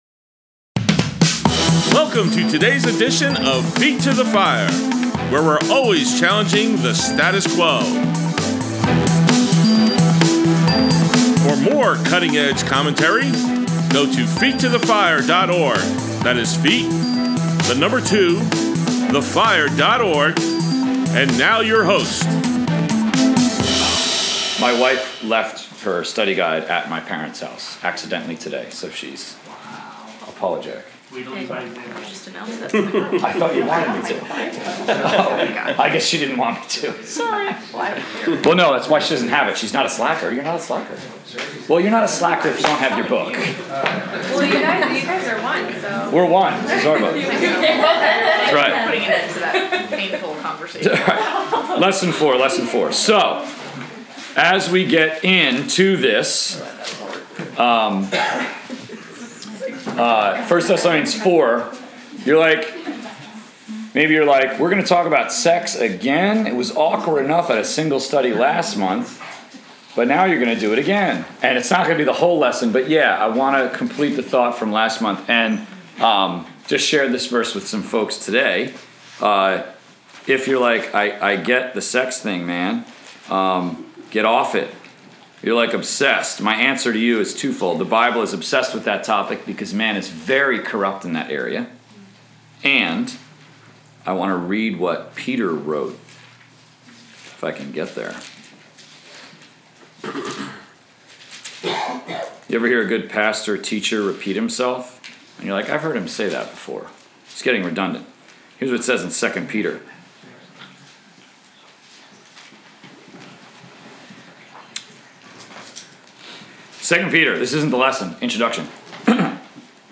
College/Career Bible Study, March 4, 2017, Part 1 of 2